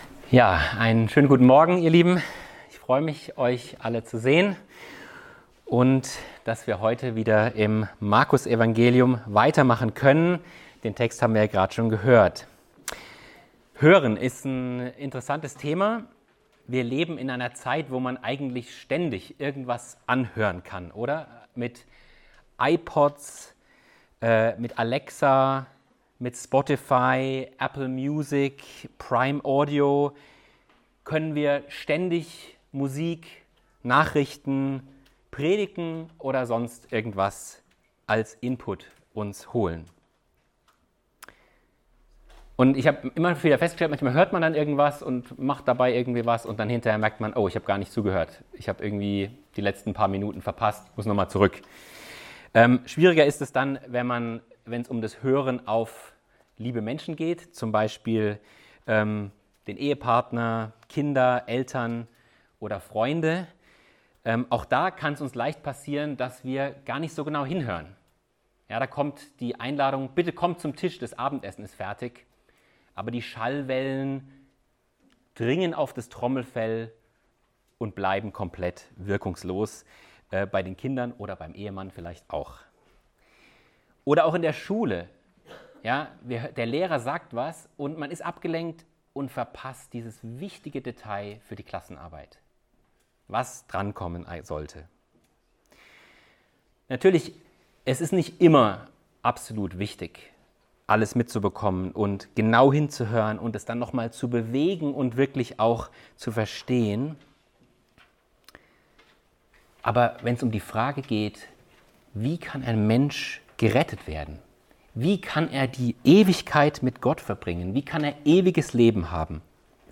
Predigtreihe: Markusevangelium Auslegungsreihe